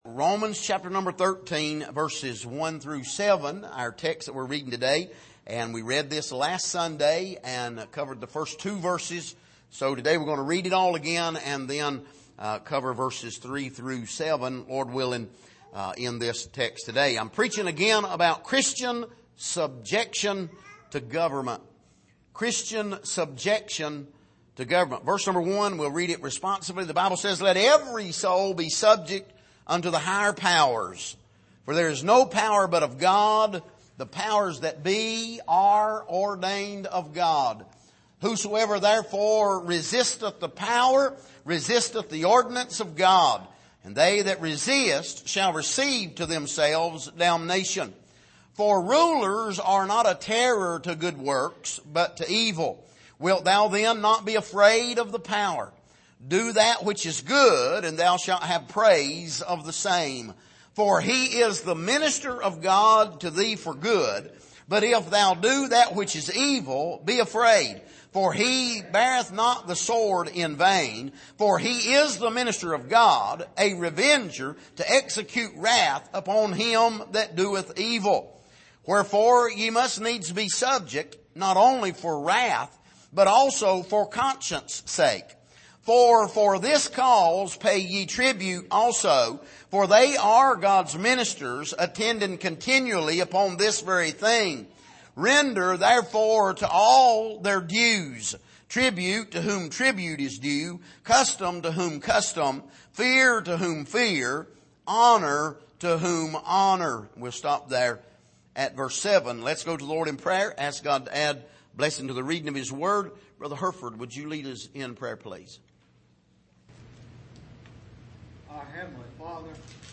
Passage: Romans 13:1-7 Service: Sunday Morning